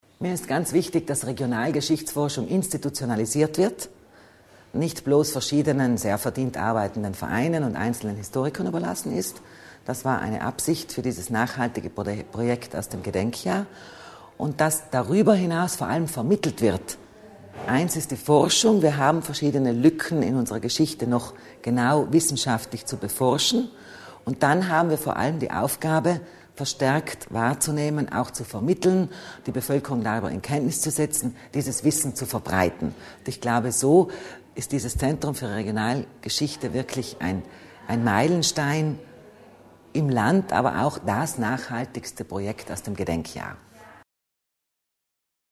Landesrätin Kasslatter Mur über die Ziele der Initiative